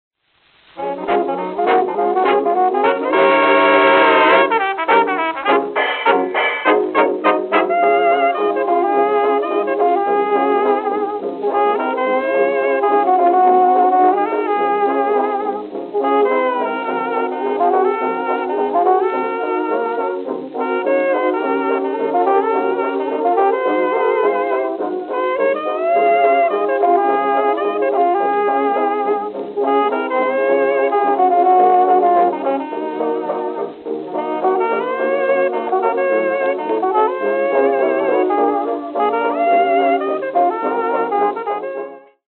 Fox trot
4kHz Brickwall Filter